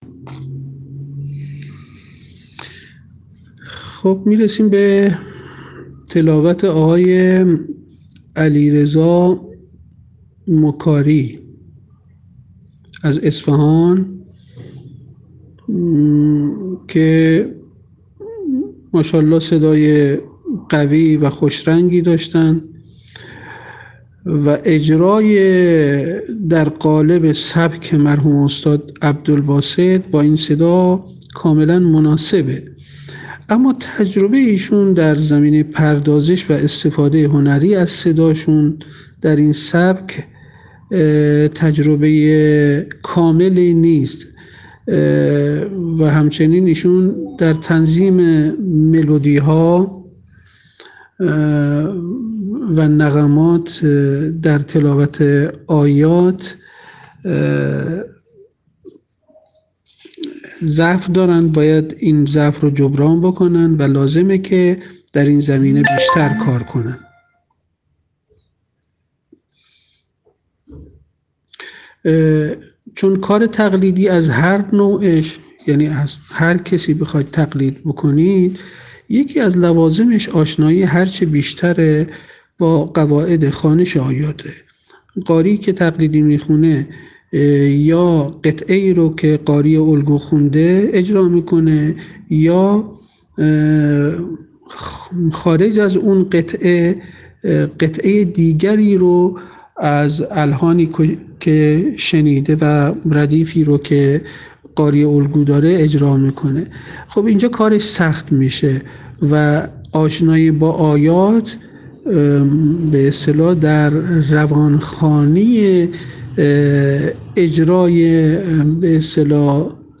شش قاری، شب گذشته، در روز دوم مسابقات سراسری قرآن به تلاوت آیاتی از کلام‌الله مجید پرداختند، ‌آشنا نبودن قاریان با آیات، ‌ضعف و خستگی صدا و اشتباه در اجرای آکسان‌ها از جمله اشکالات قاریان در این روز بود.
ایشان صدای قوی و خوش‌رنگی دارد و اجرایی در قالب سبک مرحوم استاد عبدالباسط داشت. این سبک تلاوت کاملاً مناسب صدای ایشان است اما تجربه ایشان در زمینه پردازش و استفاده هنری از صدا در این سبک، تجربه کاملی نیست.
ایشان در تنظیم ملودی‌ها و نغمات در تلاوت آیات ضعف داشته و باید این ضعف را جبران کند.